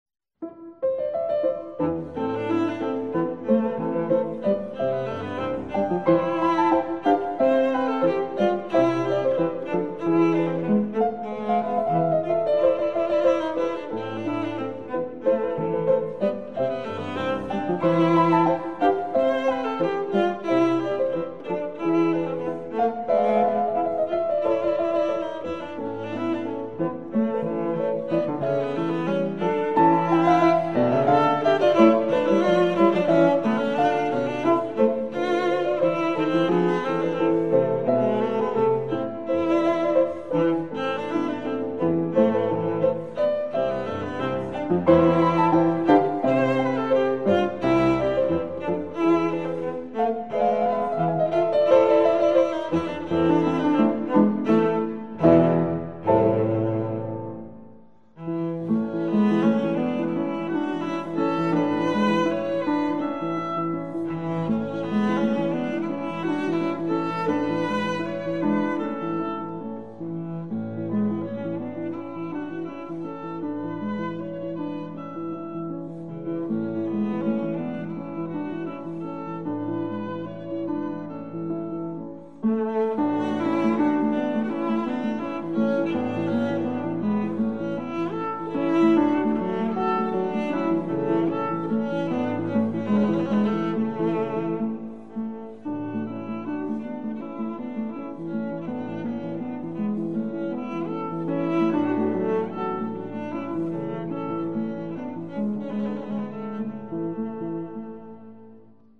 The pieces are inthe Romantic style.